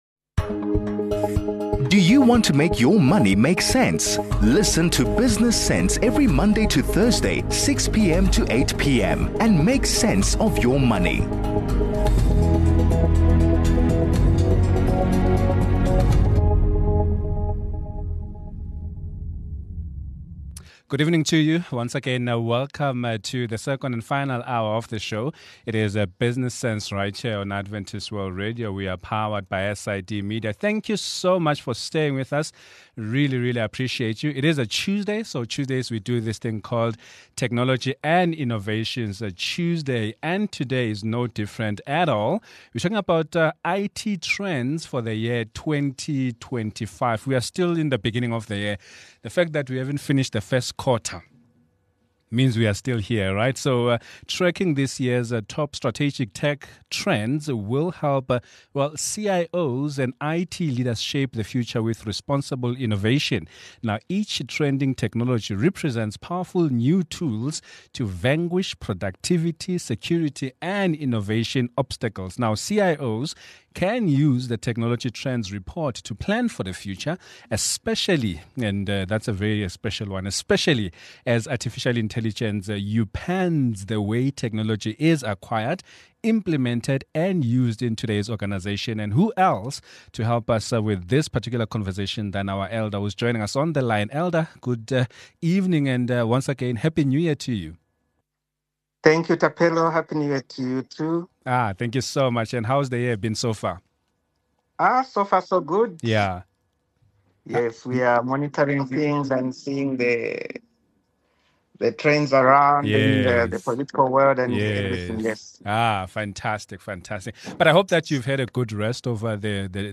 In today’s conversation, we explore trends in IT for 2025.